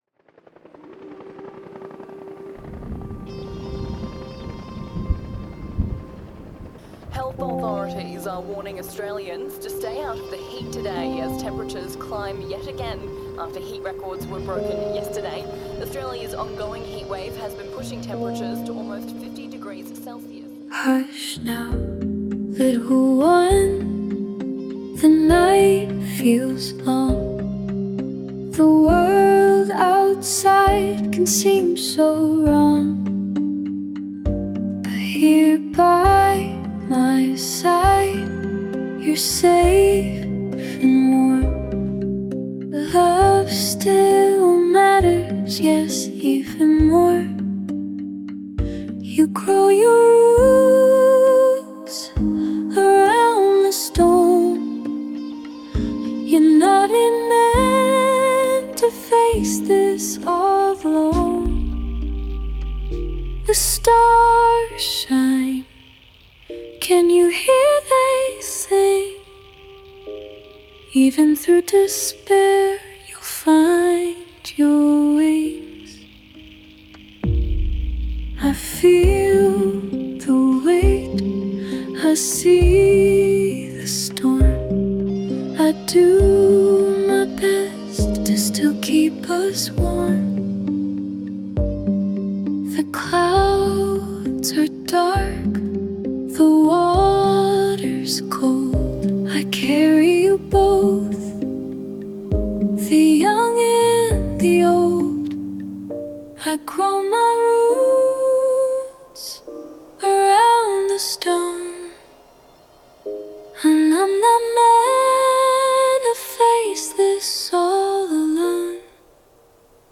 – A tender and hopeful lullaby that comforts a child – and the parent – through climate fears, reminding them they’re not alone and that love and resilience will endure.